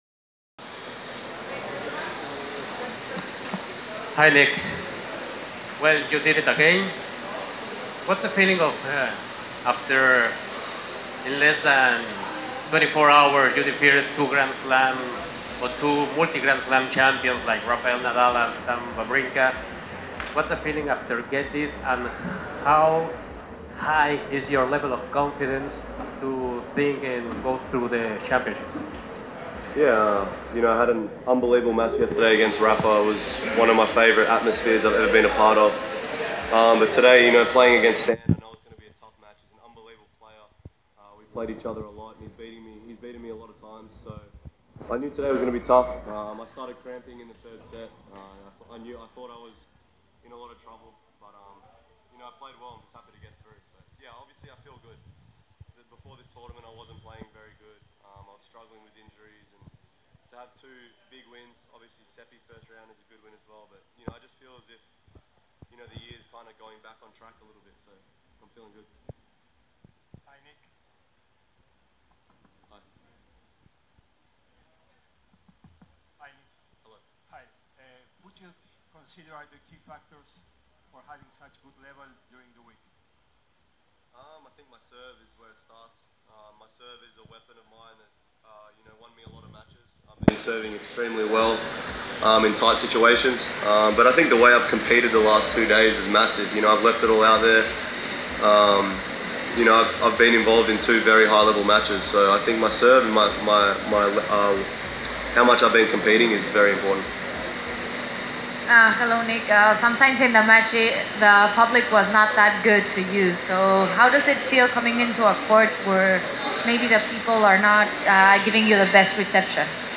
Press Conference – Nick Kyrgios (28/02/2019)